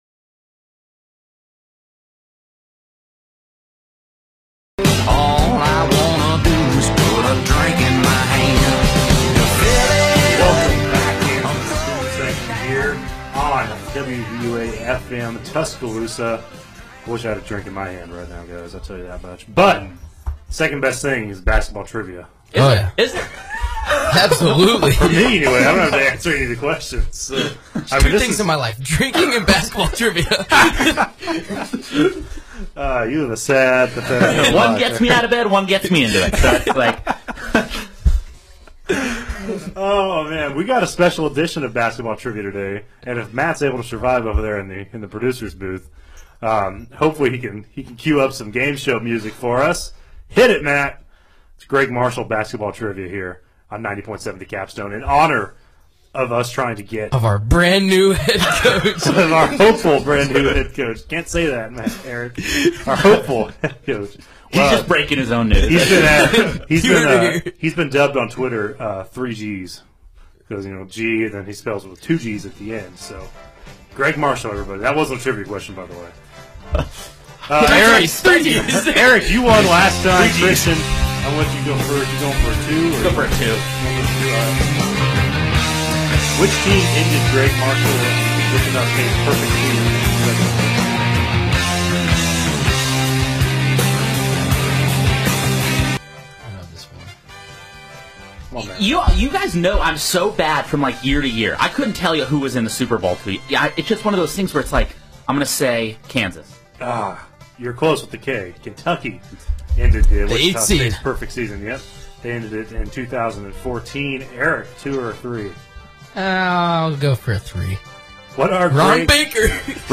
WVUA-FM's flagship sports talk show: The Student Section